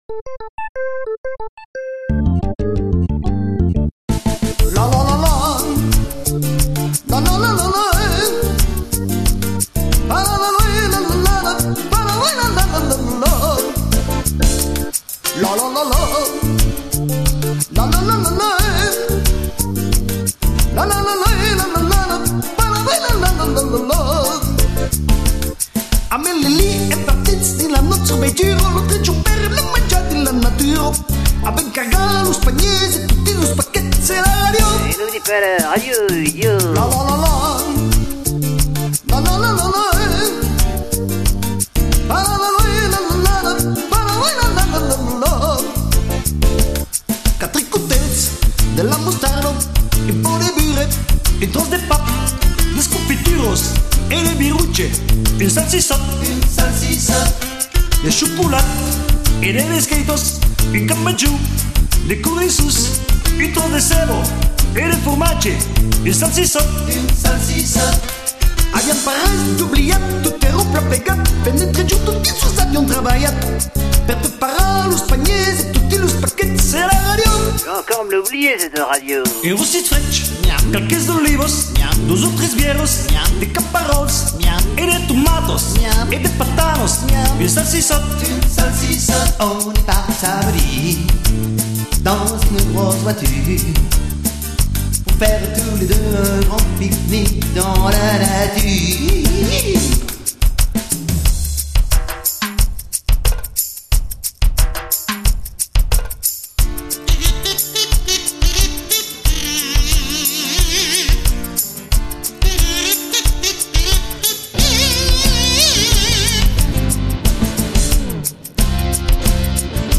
rock délire en occitan !!